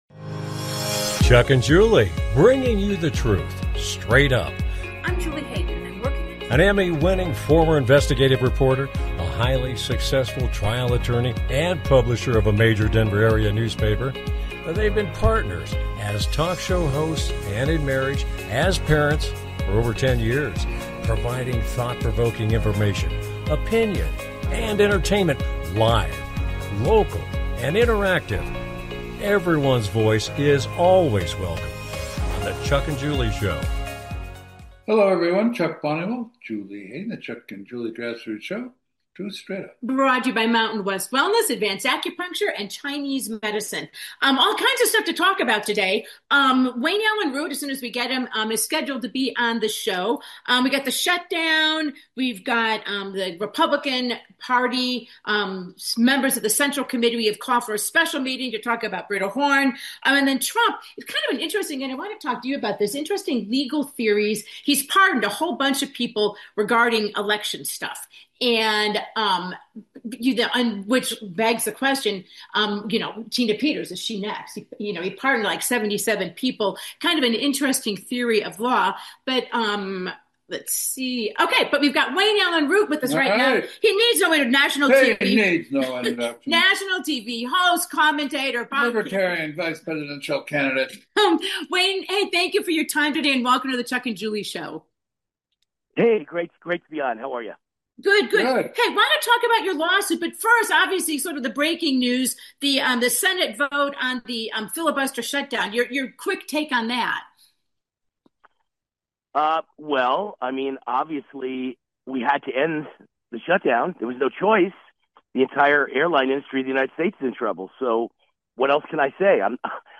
Guest, Wayne Allyn Root - Dems cave and Senate votes to end shutdown and Allyn Root on his 100 million lawsuit